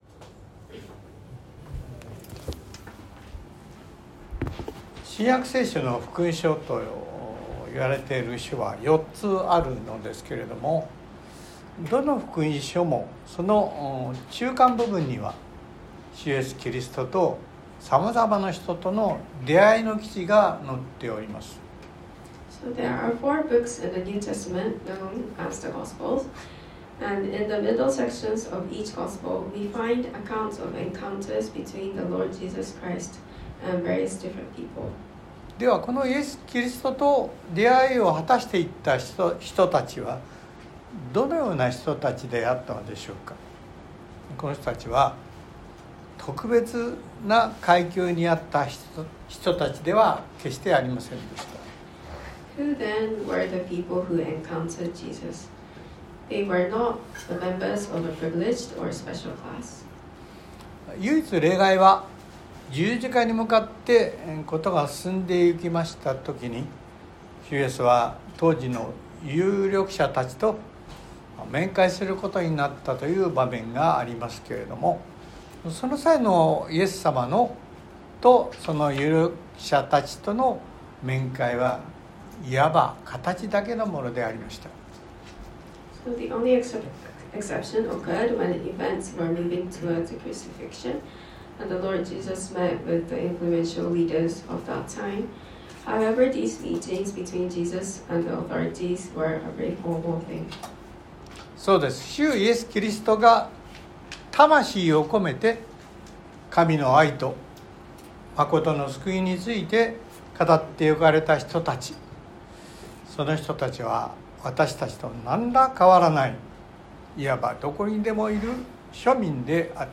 Sorry, this post is no translate, only available in Japanese.
↓Audio link to the sermon:(Sunday worship recording) (If you can’t listen on your iPhone, please update your iOS) Sorry, this post is no translate, only available in Japanese.